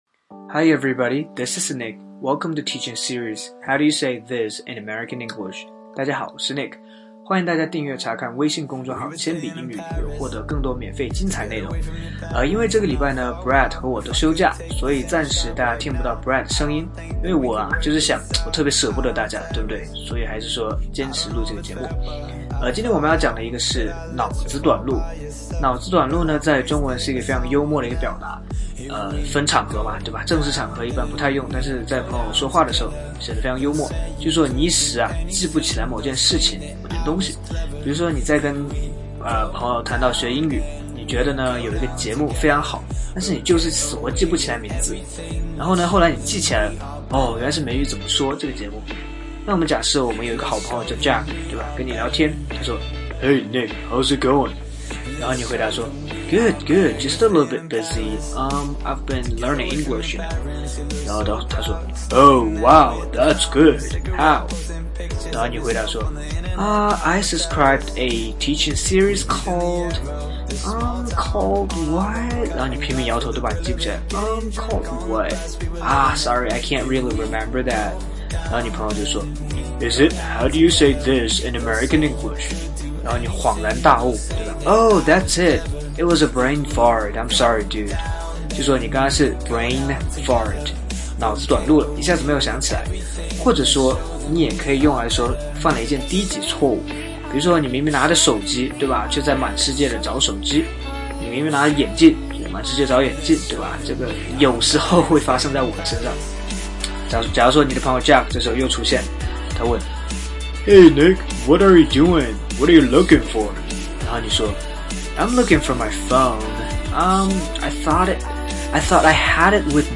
在线英语听力室全网最酷美语怎么说:第19期 脑子短路的听力文件下载, 《全网最酷美语怎么说》栏目是一档中外教日播教学节目，致力于帮大家解决“就在嘴边却出不出口”的难题，摆脱中式英语，学习最IN最地道的表达。